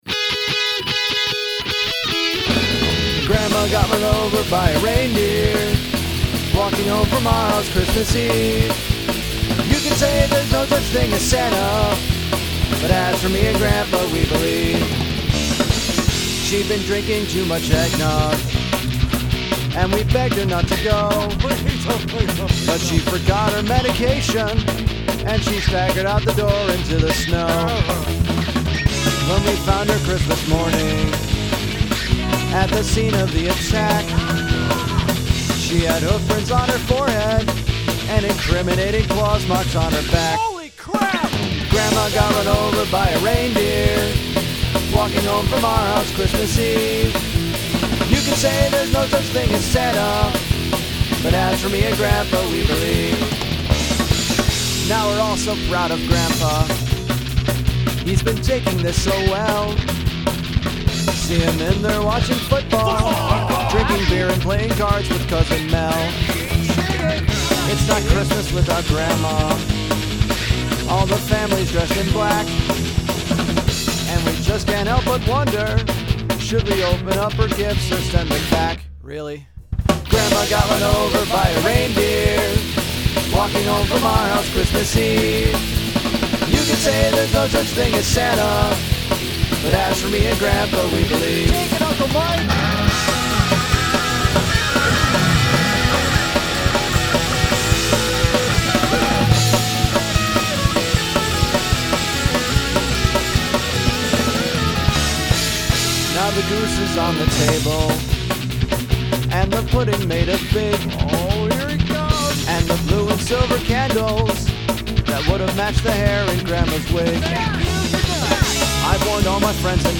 Guitar solo
Football fans